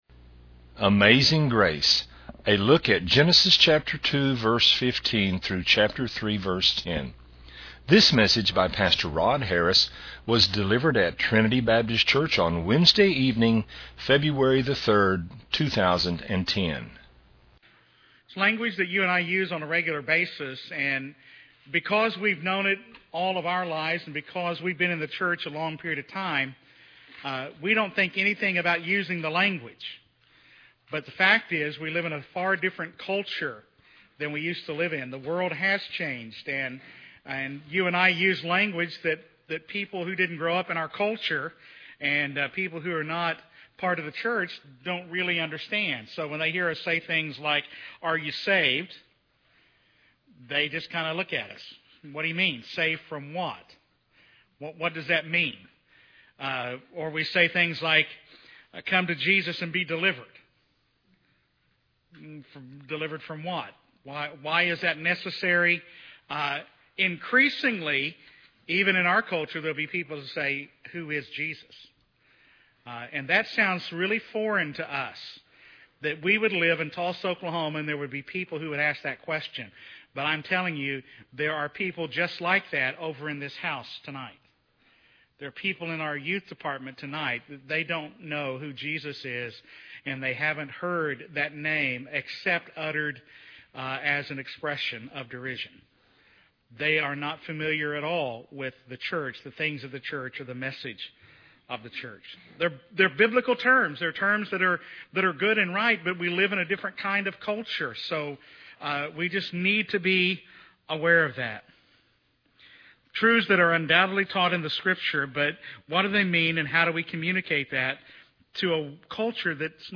A Bible study from Genesis 2:15-3:10.